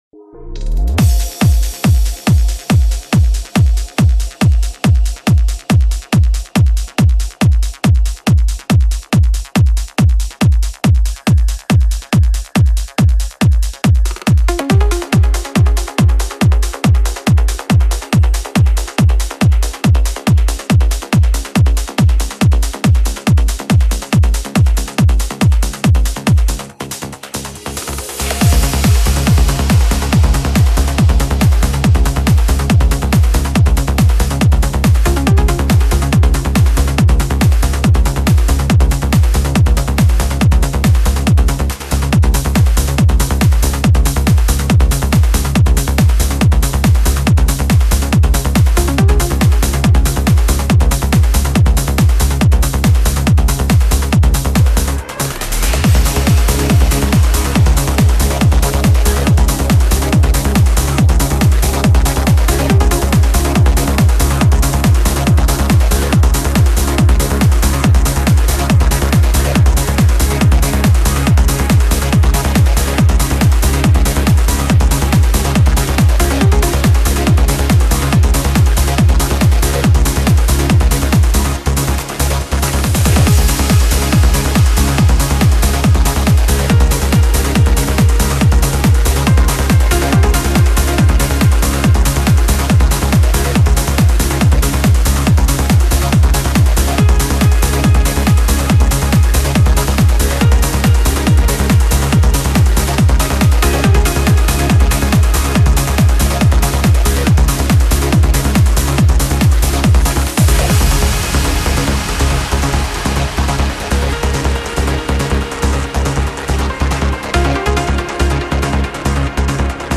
آهنگ بی کلام
در سبک Melodic & Dream Trance